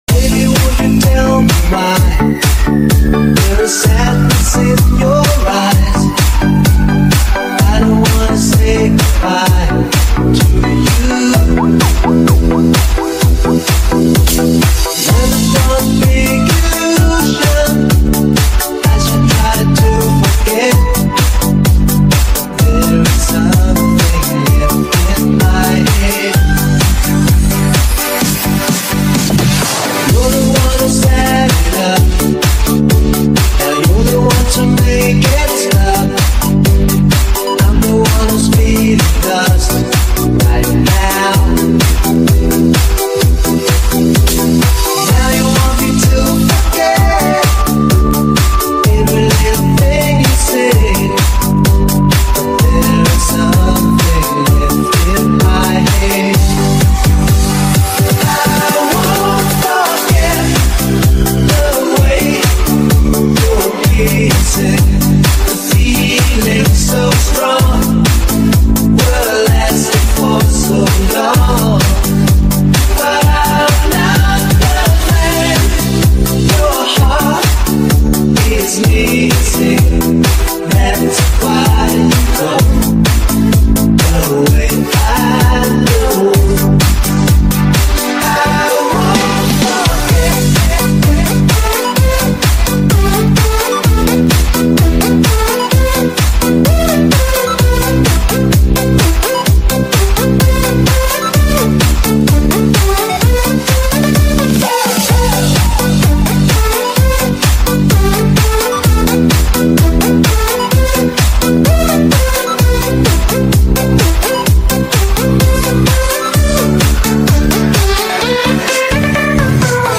ASMR GREEN EMOJI FOOD kohakuto sound effects free download
ASMR GREEN EMOJI FOOD kohakuto ice mukbang eating sounds